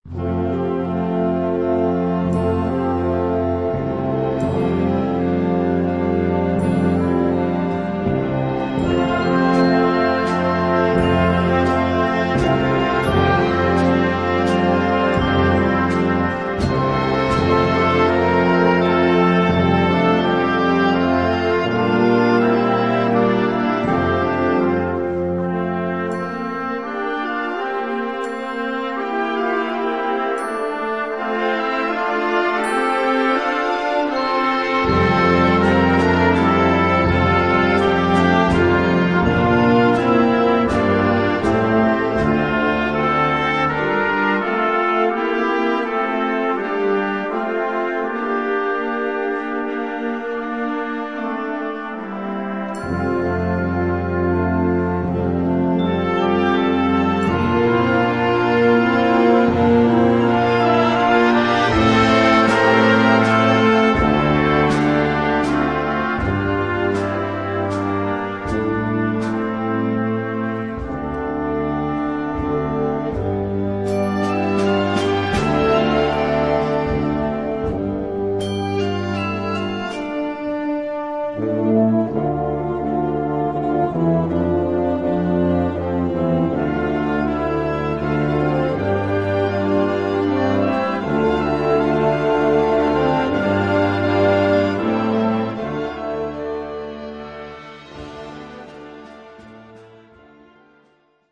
23 x 30,5 cm Besetzung: Blasorchester Tonprobe